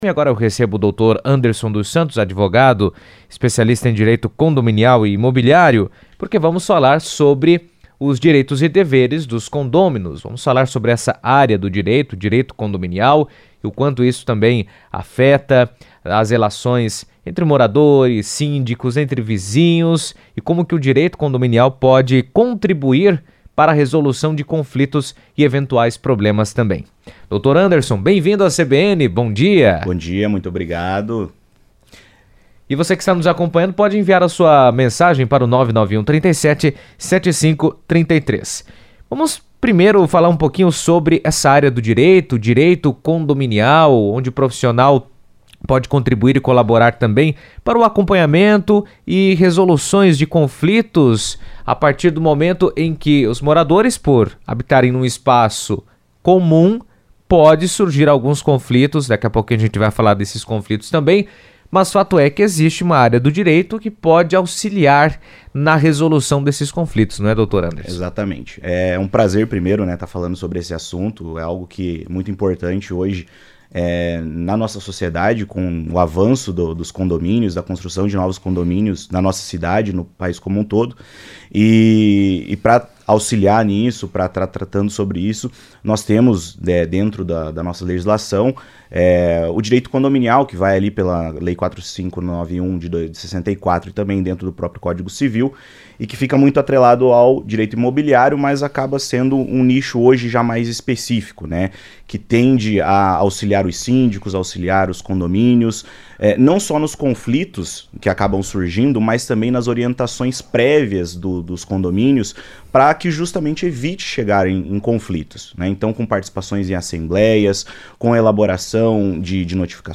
esteve na CBN explicando pontos essenciais para uma boa gestão e convivência nos condomínios.